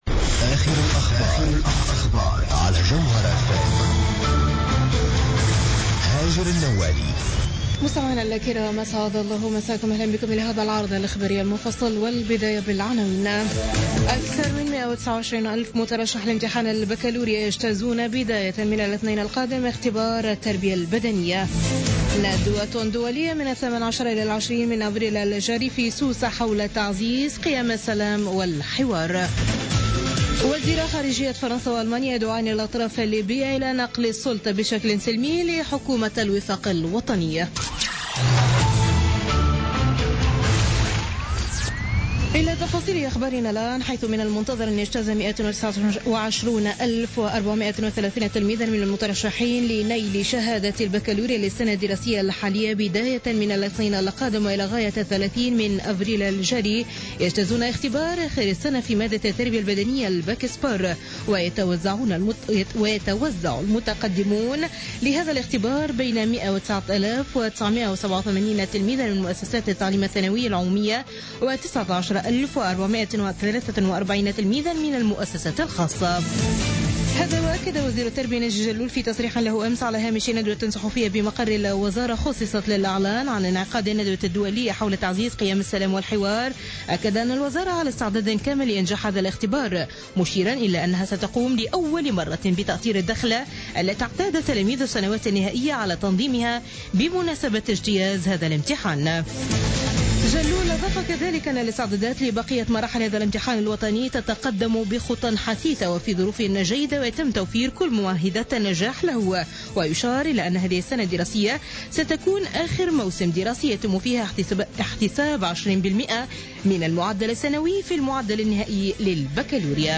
نشرة أخبار منتصف الليل ليوم الأحد 17 أفريل 2016